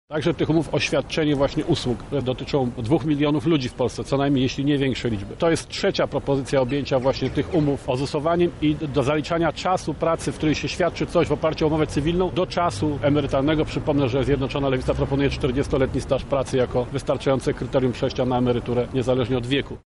Przede wszystkim chcemy jednak „ozusować” i zaliczyć do emerytury wszystkie umowy cywilne – tłumaczy Janusz Palikot, przewodniczący Twojego Ruchu.